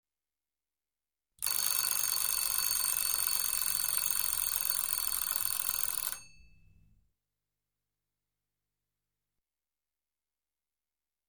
Weckerklingeln.mp3